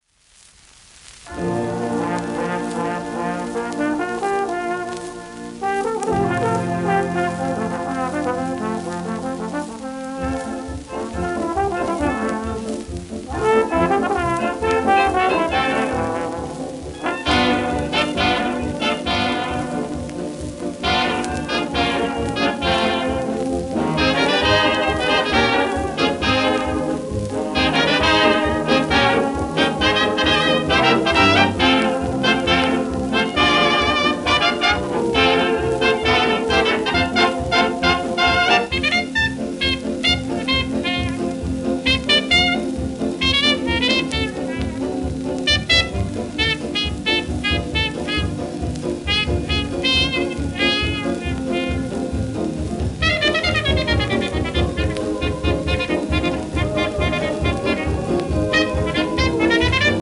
盤質B+ *薄い面擦れ,小キズ
1930年録音